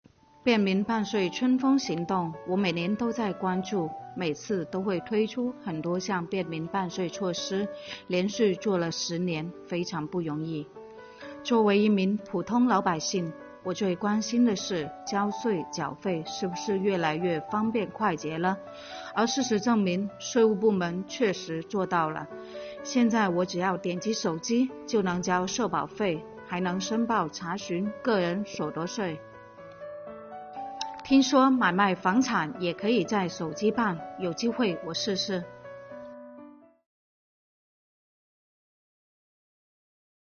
便民办税春风行动十周年系列访谈（第五期）